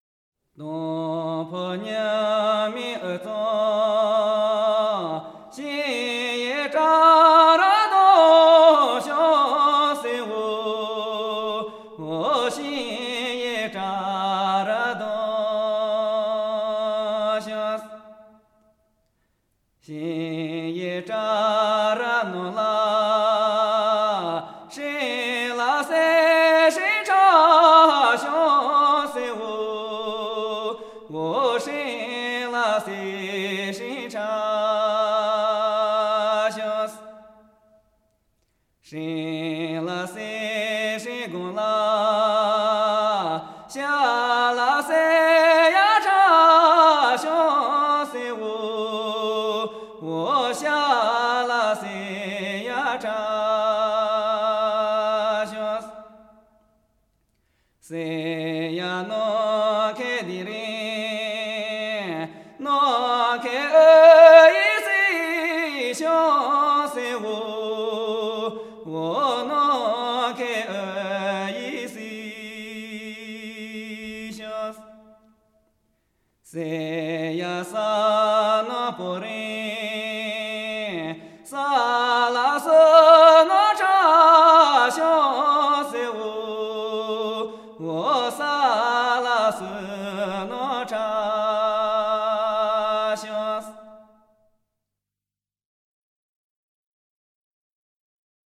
少数民族音乐系列
29首歌，旋律朴实悦耳，歌声高吭开怀，
都有嘹亮的歌声，一流的技巧。